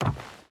Footsteps / Wood
Wood Run 2.ogg